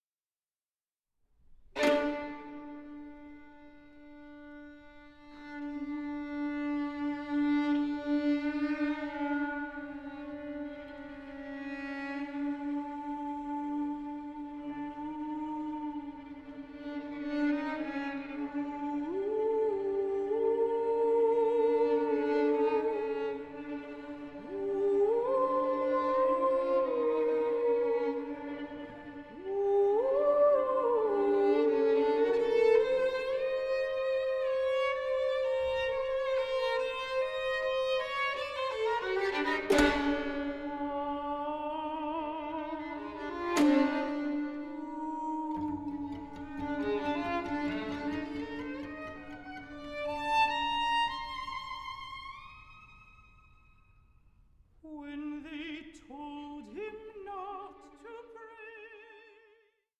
recorded live at Kohl Mansion
mezzo-soprano